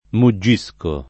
muggire v.; muggisco [ mu JJ&S ko ], -sci — per muggisce , poet. mugge [ m 2JJ e ] — es. con acc. scr.: il mar sotto le piante Della Diva muggìa [ il m # r S otto le p L# nte della d & va mu JJ& a ] (Monti)